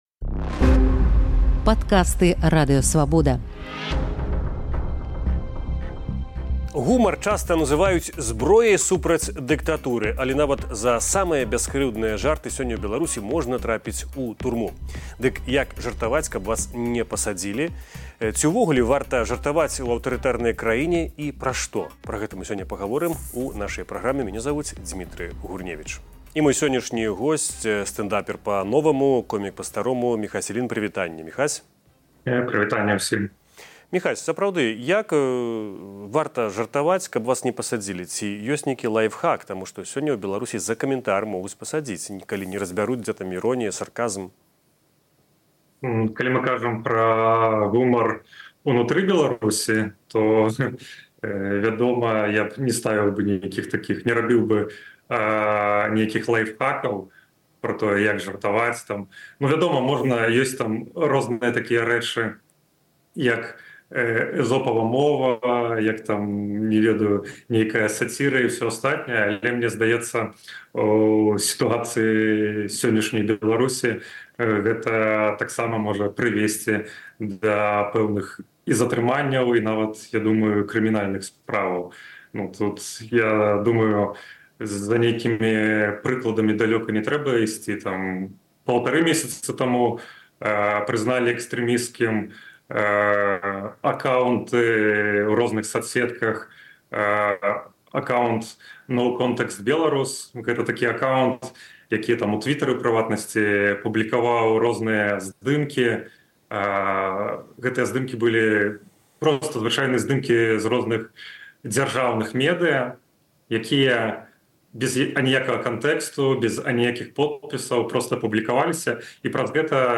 Як жартаваць з улады, каб ня сесьці, і з апазыцыі, каб ня стаць «агентурай». Гутарка са стэндапэрам